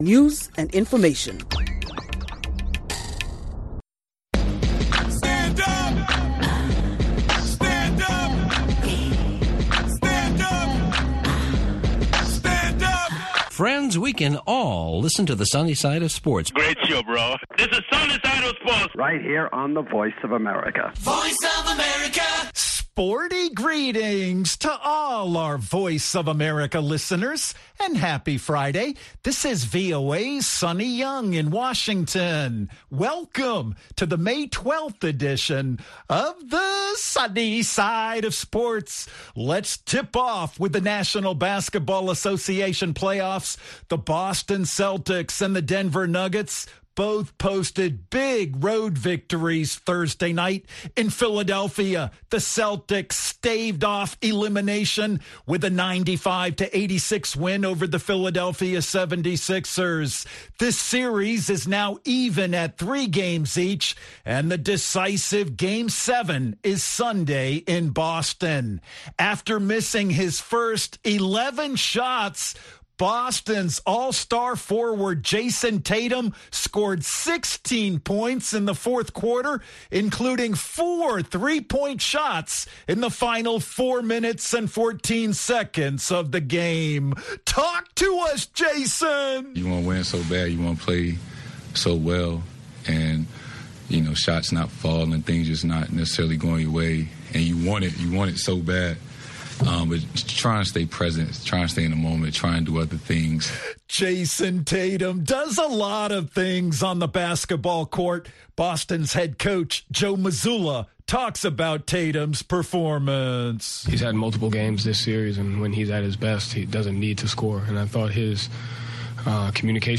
The Celtics defeated the Philadelphia 76ers, 95-86, to even their playoff series at 3-3; and the Nuggets routed the Phoenix Suns, 125-100, to win their series, 4-2, and advance to the Western Conference Finals. It'll be an audio extravaganza with reaction from some of the league's biggest stars, including Boston's Jayson Tatum, Philadelphia's 2023 NBA MVP Joel Embiid, and Denver's two-time league MVP Nikola Jokic.